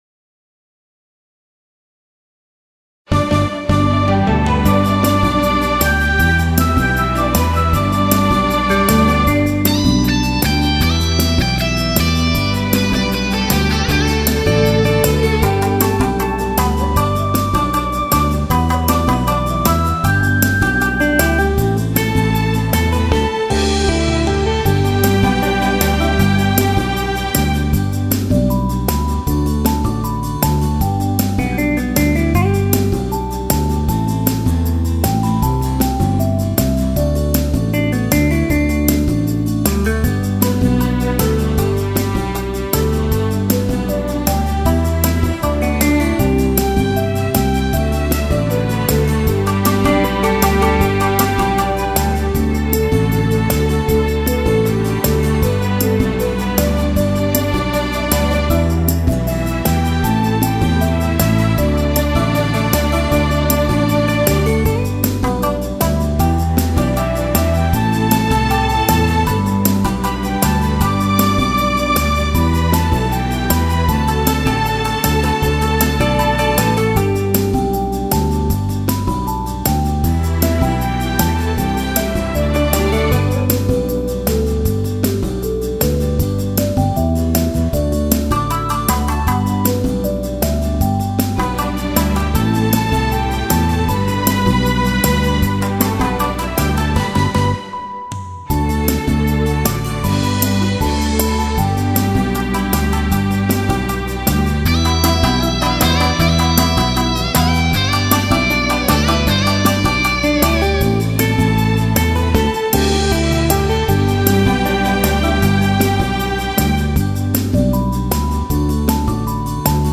三味線演歌
「娘三味線いざ出番」軽快な作品に仕上がりました。